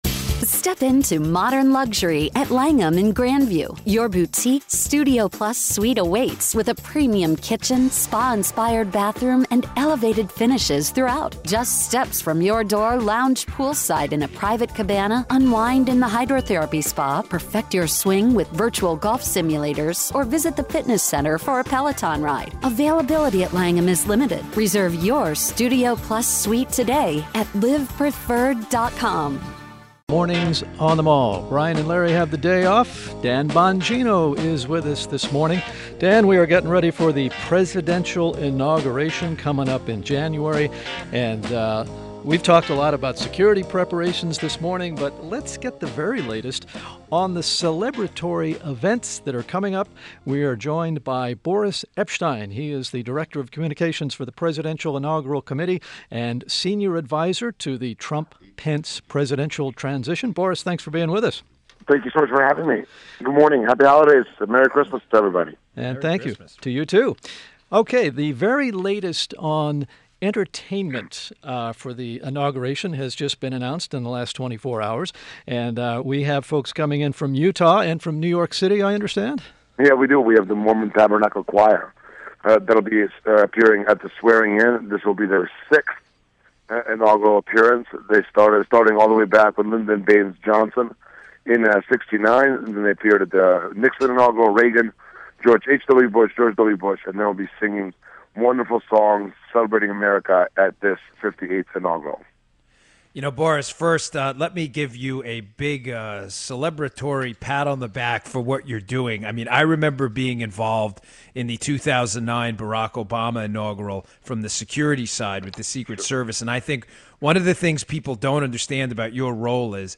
INTERVIEW – BORIS EPSHTEYN – Director of Communications, Presidential Inaugural Committee and Senior Advisor, Trump Pence Presidential Transition — discussed the latest progress on how the Trump Inauguration plans are coming together in D.C.